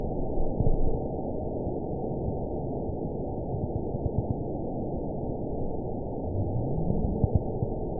event 920917 date 04/15/24 time 14:33:17 GMT (2 weeks ago) score 8.39 location TSS-AB10 detected by nrw target species NRW annotations +NRW Spectrogram: Frequency (kHz) vs. Time (s) audio not available .wav